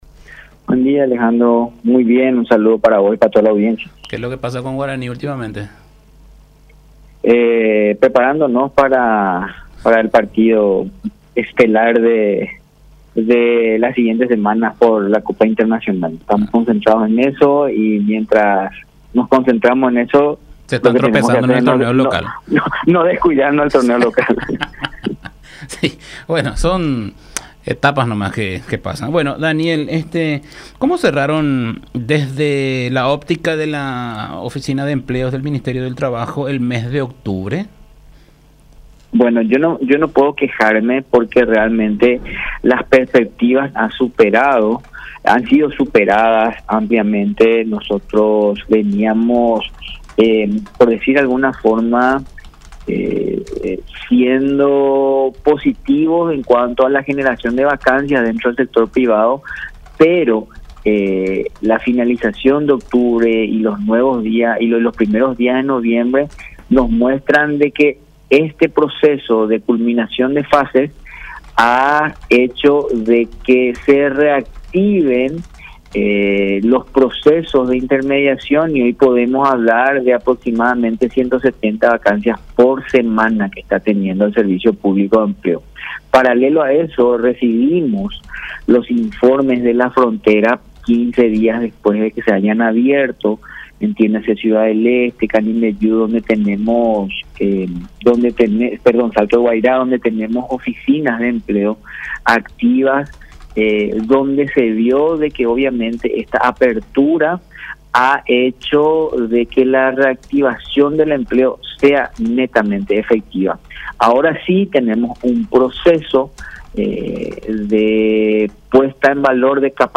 señaló Sánchez en entrevista con La Unión R800 AM.